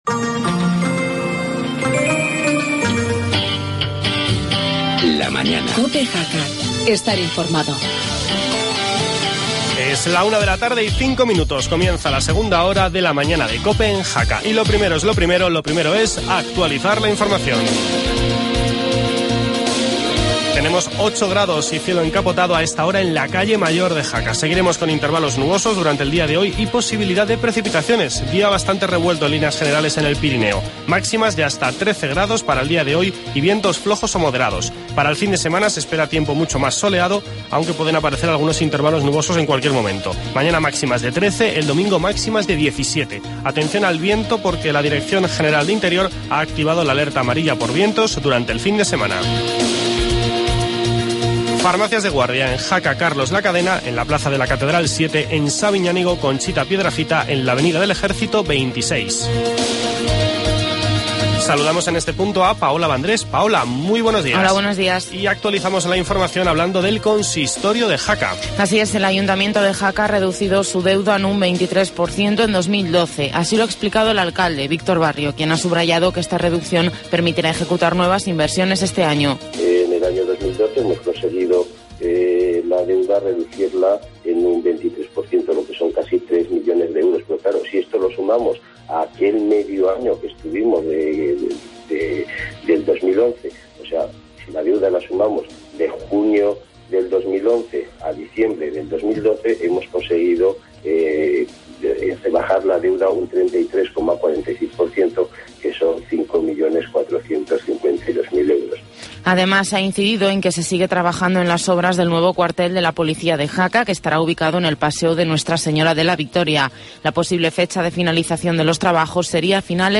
Hablamos con Teresa Rabal que mañana actuará en Jaca con su espectáculo "Veo, Veo, el Musical".